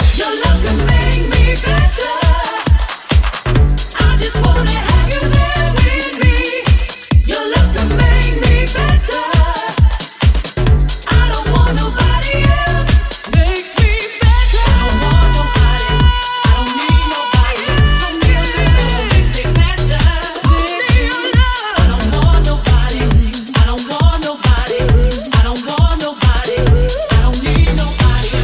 house
Genere: house, deep house, remix